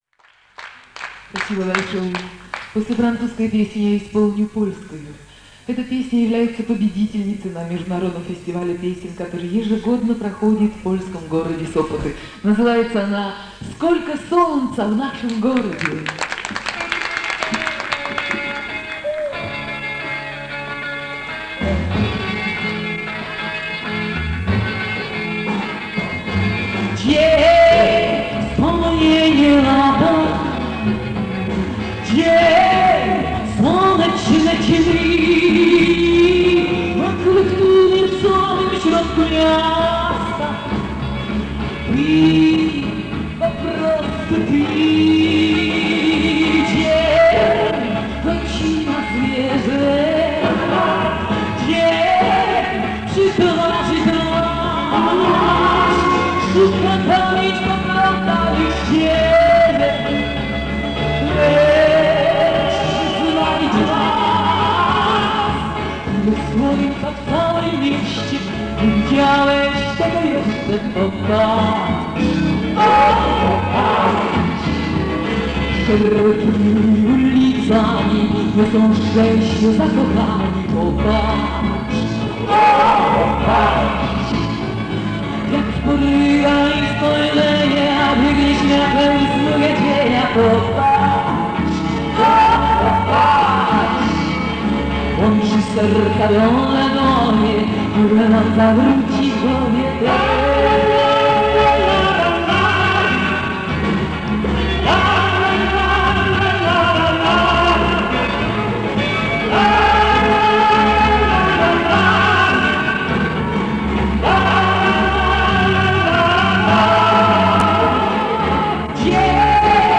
(Конц.исполнение)